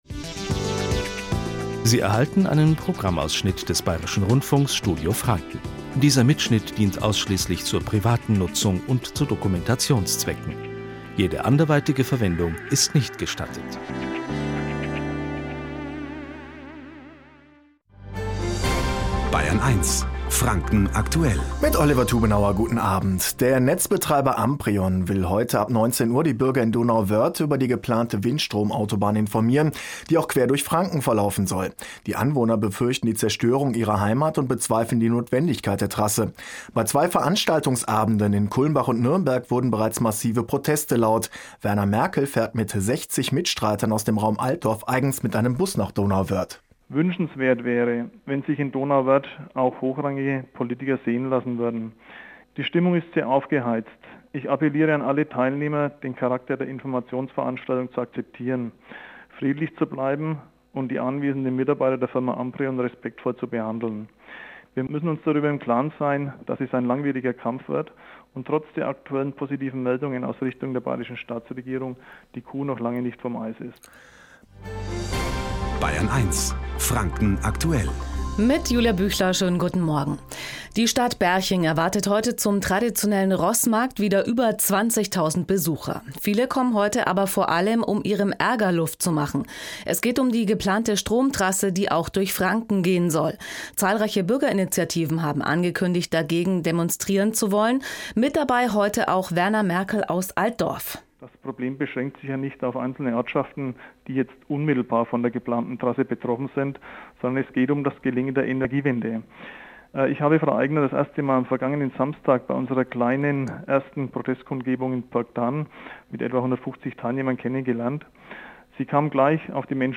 Radiointerview
Download des Beitrages: Bayern 1 Interview